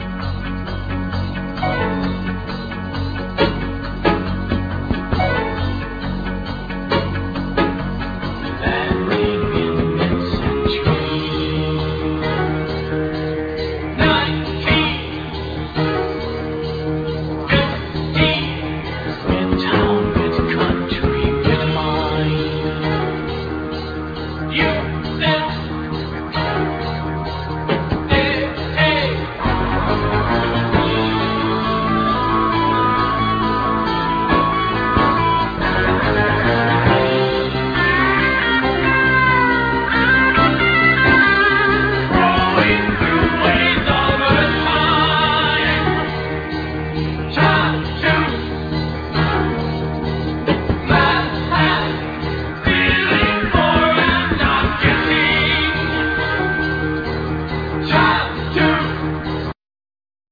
drums
tenor sax
trumpet
bass guitar